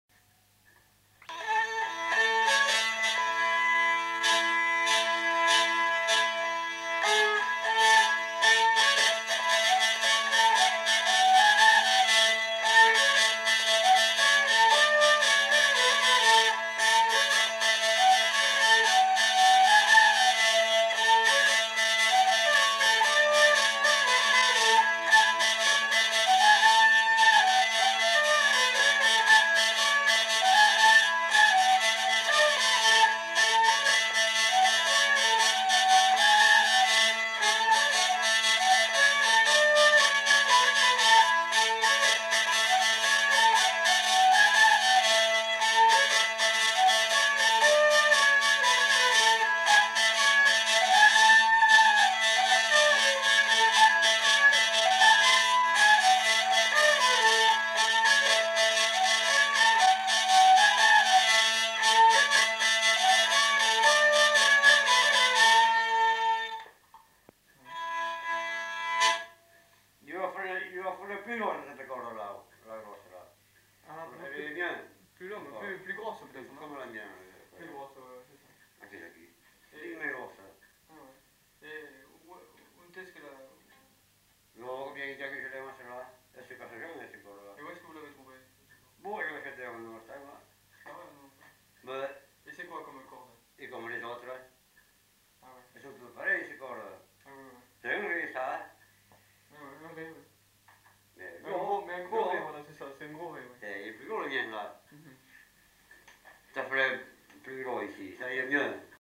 Aire culturelle : Gabardan
Genre : morceau instrumental
Instrument de musique : vielle à roue
Danse : rondeau